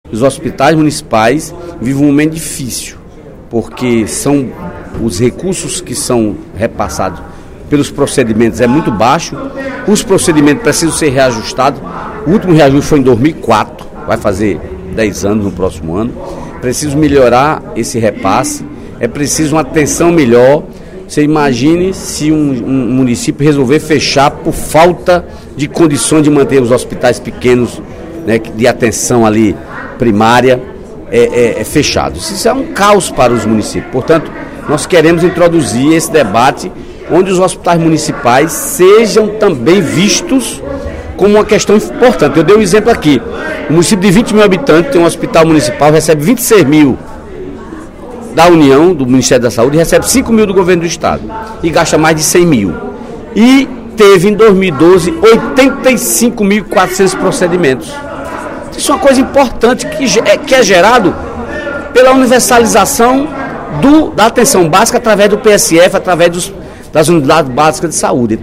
O deputado Dedé Teixeira (PT), no primeiro expediente da sessão desta sexta-feira (08/03), ocupou a tribuna do Plenário 13 de Maio para pedir mais atenção do poder público aos hospitais municipais.